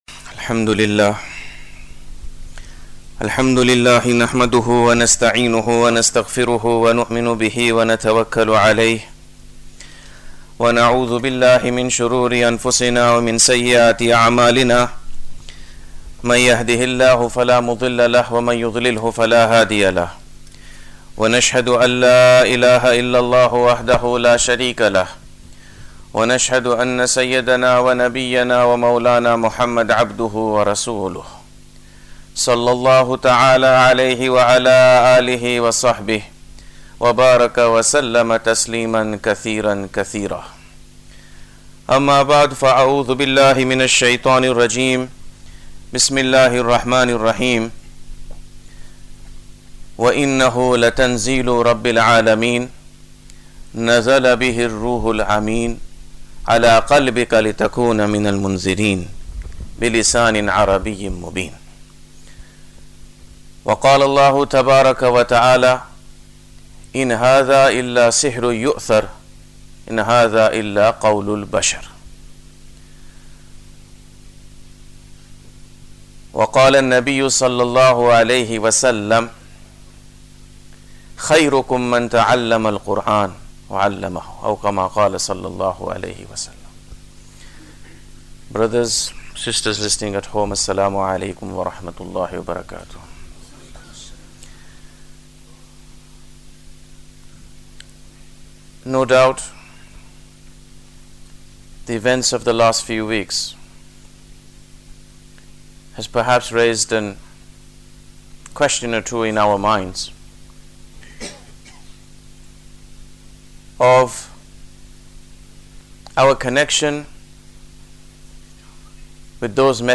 The first session in a special monthly series of Duroos (lessons) on the Noble Qur’an. Delivered at Masjid e Salaam, Preston